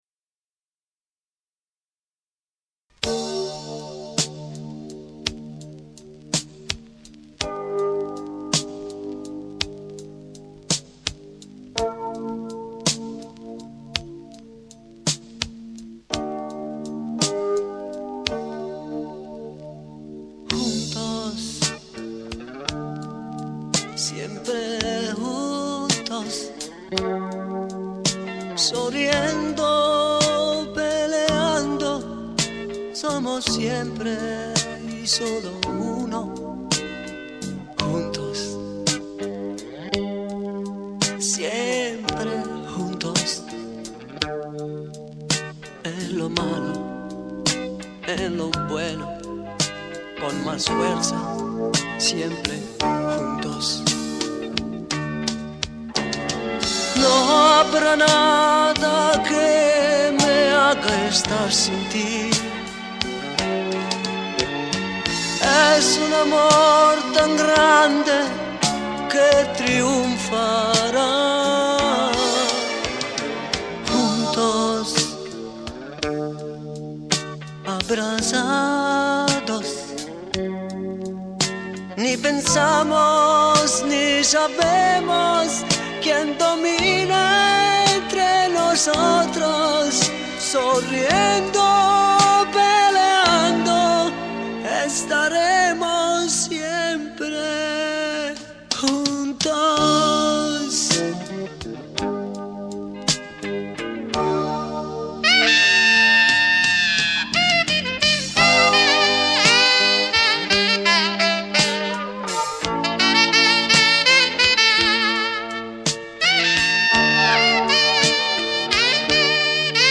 Coro
Batteria
Chitarra
Basso
Pianoforte, Sintetizzatori e Tastiere